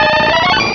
Cri d'Aéromite dans Pokémon Rubis et Saphir.